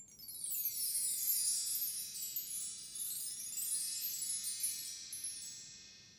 spinnerspin.wav